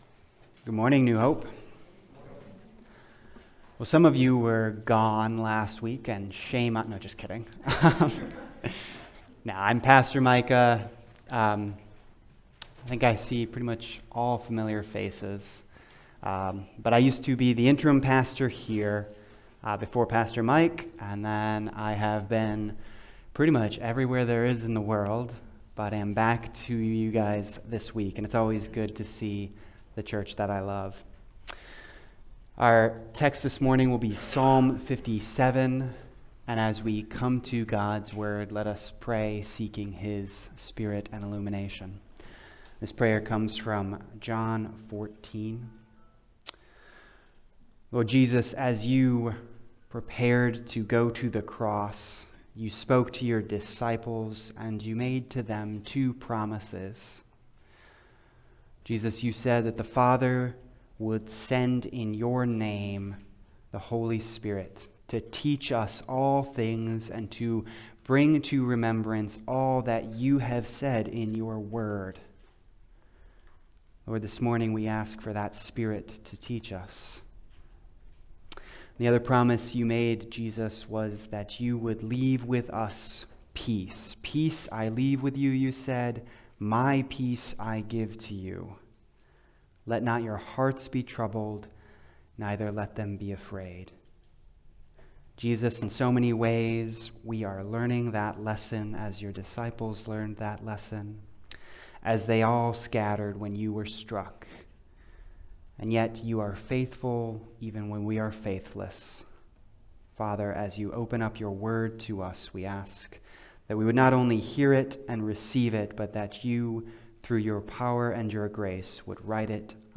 Passage: Psalm 57 Service Type: Sunday Service